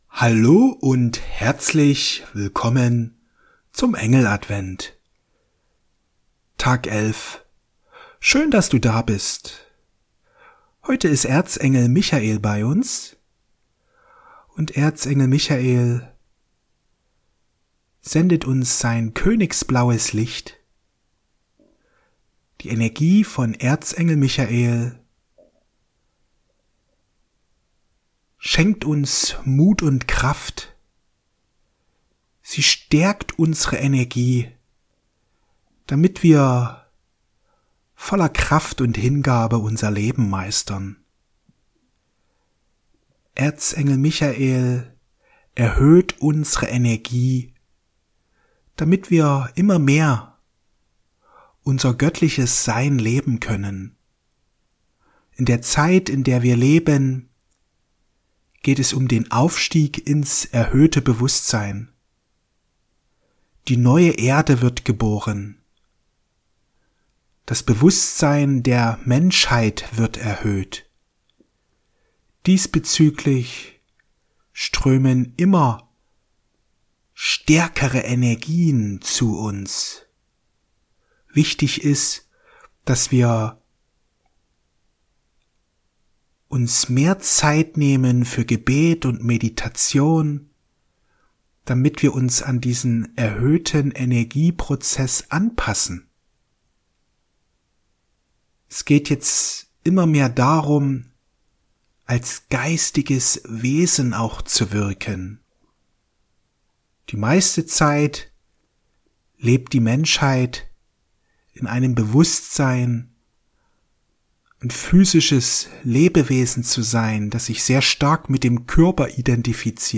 Kraft und Power für dein Leben Meditation mit Erzengel Michael